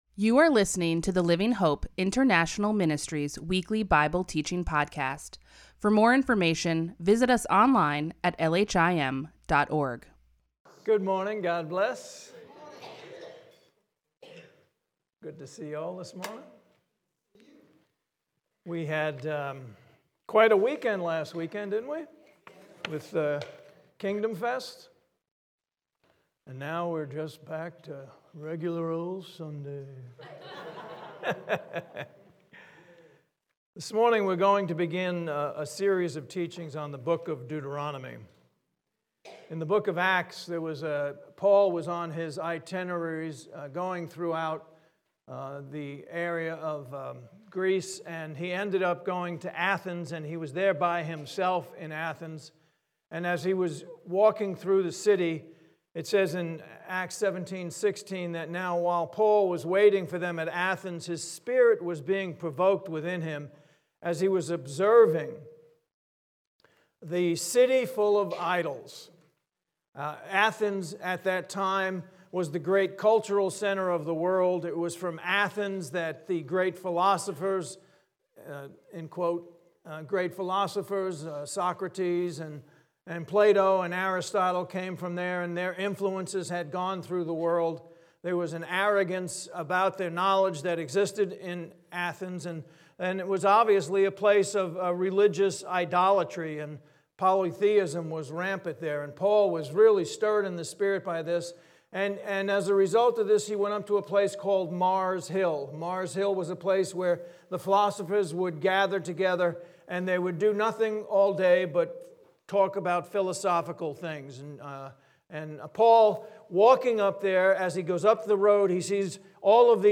LHIM Weekly Bible Teaching
Teaching.mp3